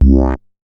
MoogVoice 003.WAV